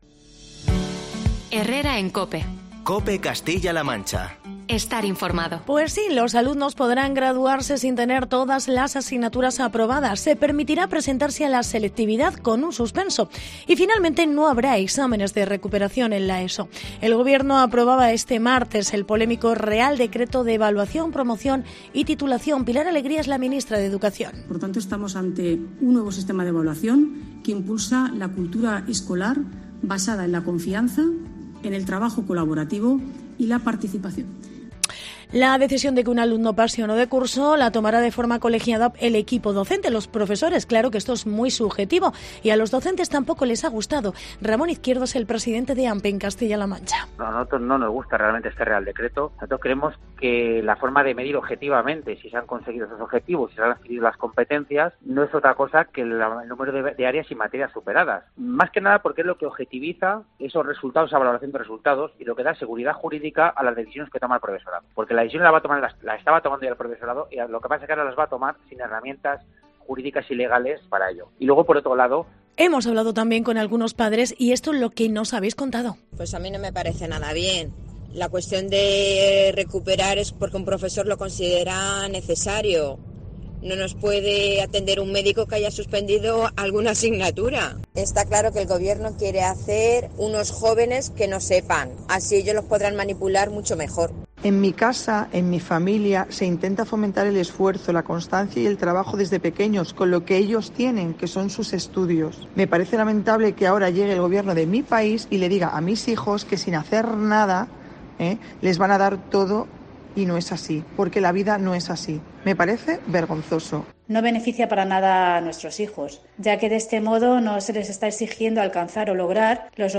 Reportaje Lomloe